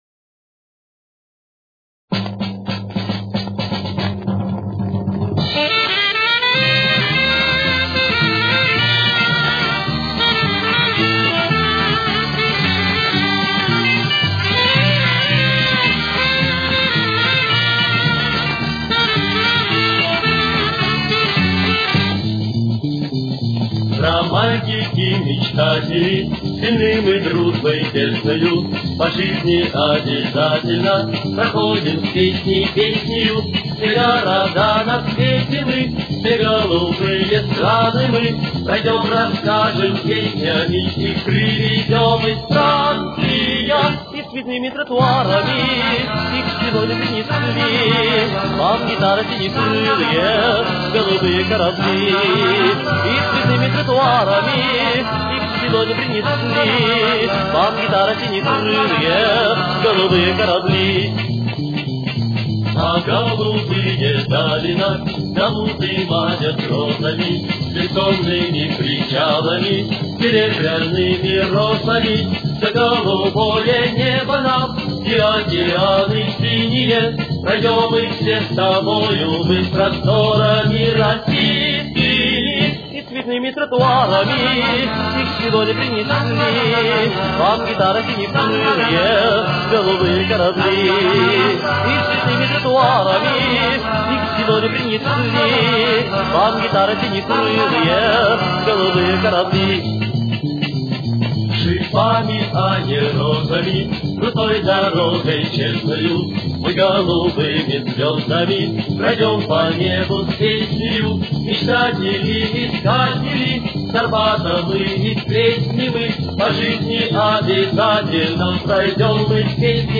с очень низким качеством (16 – 32 кБит/с)
Тональность: Си-бемоль мажор. Темп: 213.